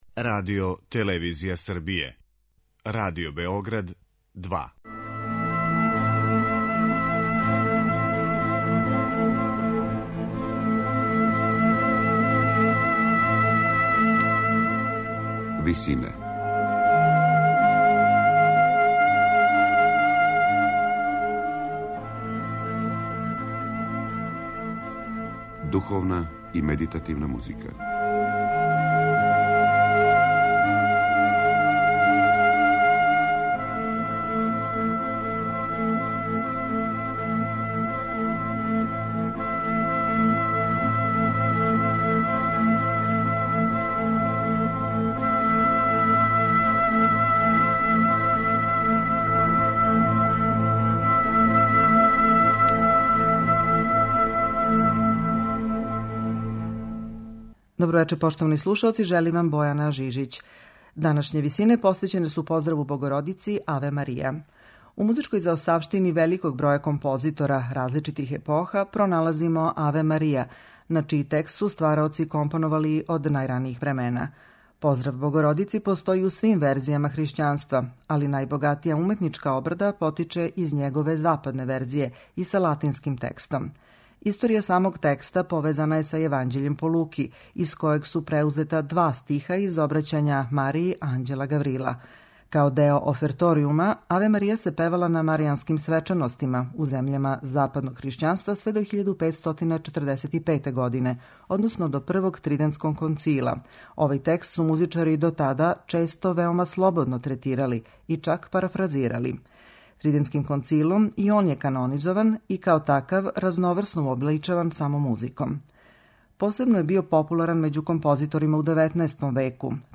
У данашњој емисији и у архивском снимку Радио Београда, моћи ћете да чујете како су Аве Марија музички уобличили Јакоб Аркаделт, Никола Гомбер, Франц Лист, Шарл Гуно на основу Баховог прелудијума, Антон Брукнер, Феликс Менделсон, Анри Пусер, Луиђи Луци, Франческо Тости и Ђулио Качини.